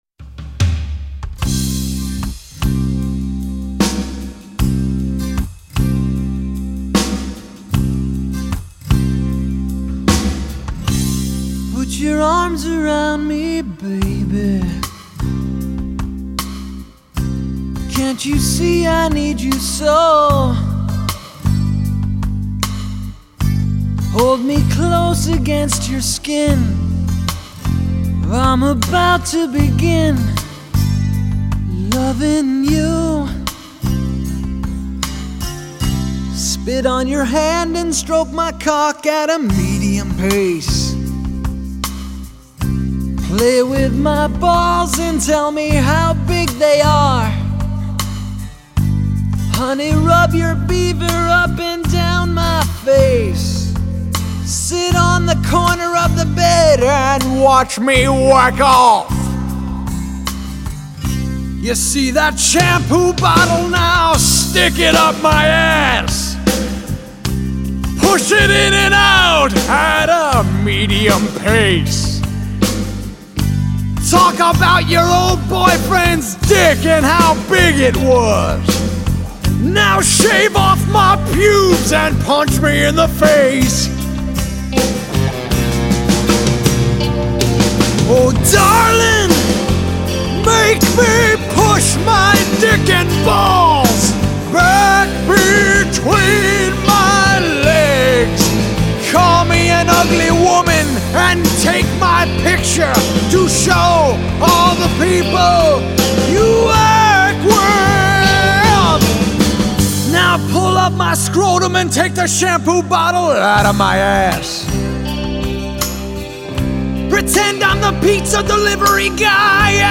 Tags: Funny Comedy Rock Music Cool adult